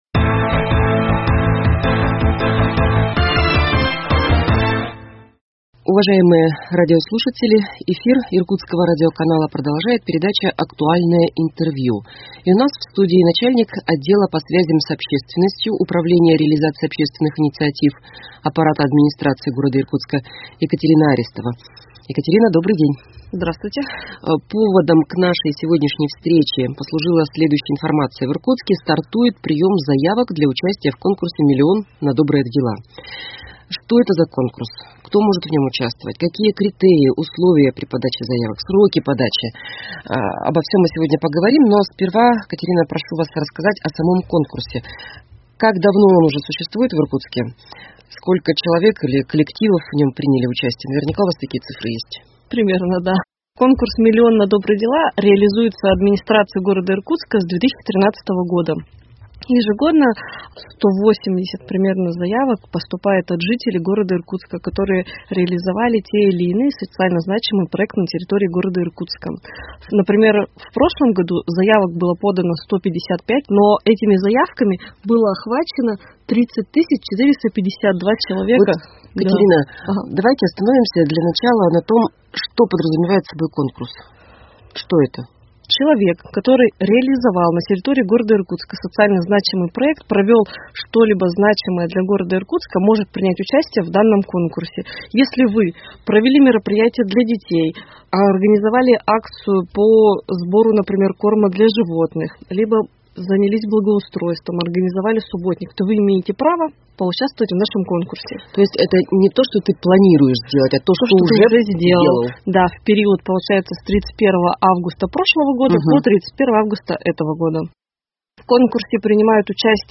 Актуальное интервью: Конкурс «Миллион на добрые дела» 06.09.2021